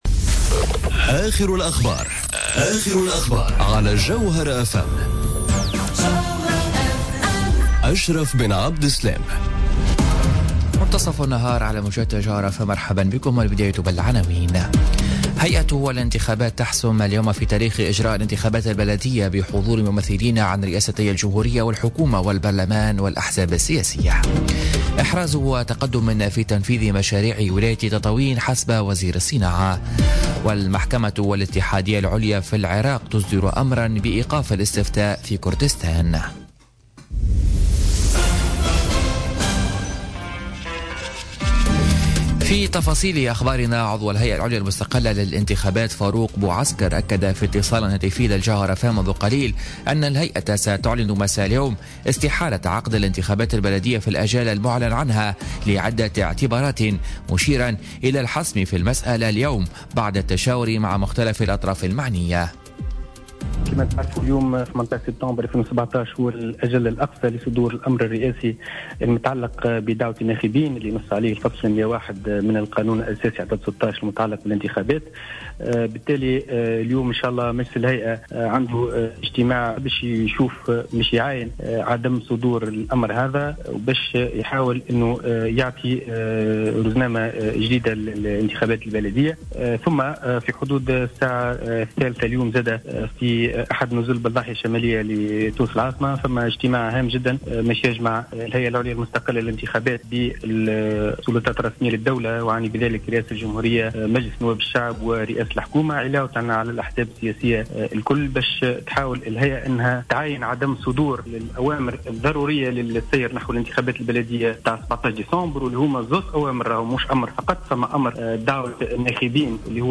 Journal Info 12h00 du lundi 18 Septembre 2017